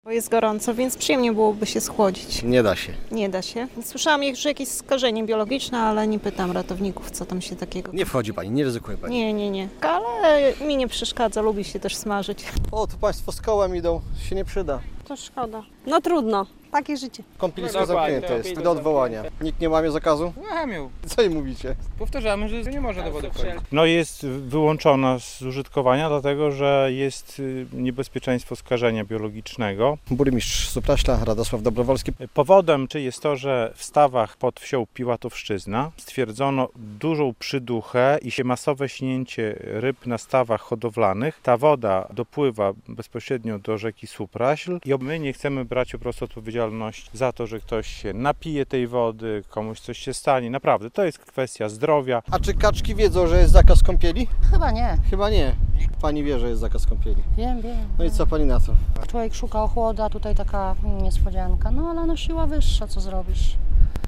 Radio Białystok | Wiadomości | Wiadomości - Na plaży miejskiej w Supraślu obowiązuje zakaz kąpieli